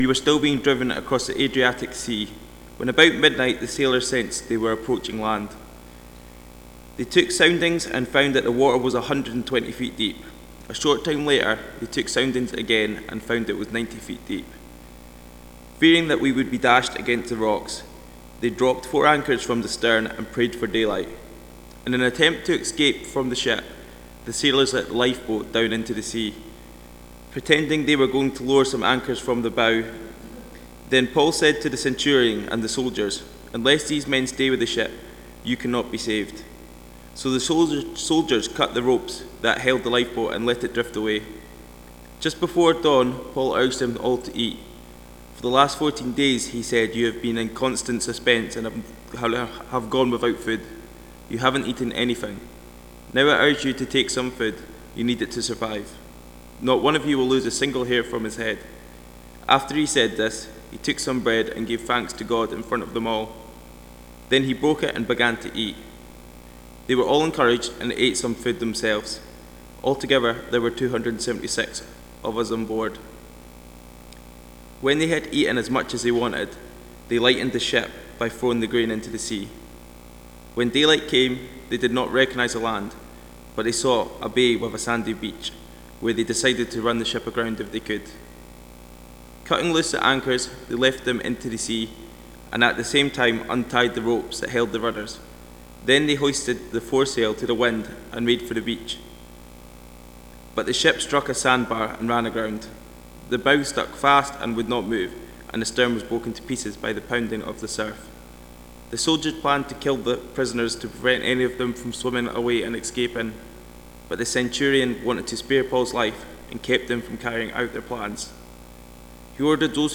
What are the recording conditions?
Acts Passage: Acts 27:27-44 Service Type: Sunday Morning « “Do not be afraid” Paul preaches at Rome under guard »